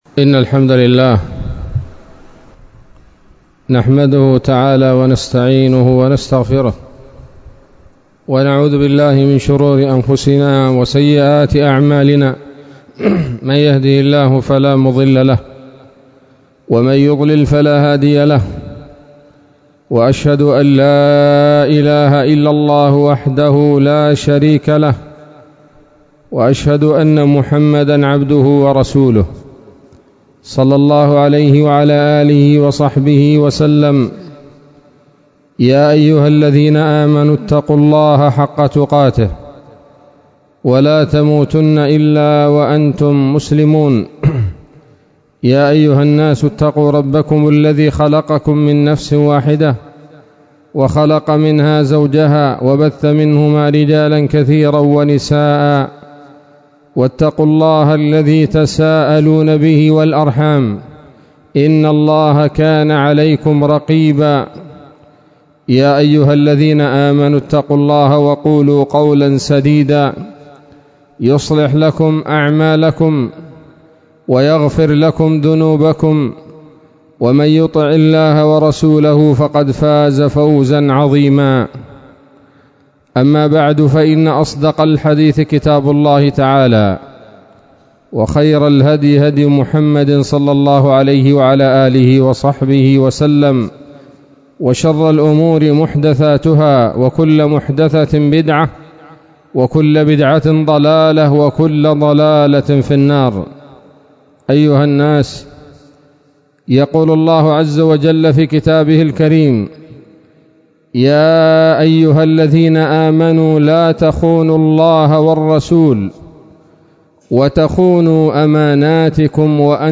خطبة جمعة بعنوان: (( تحذير المؤمنين من الركون إلى الخائنين )) 17 صفر 1443 هـ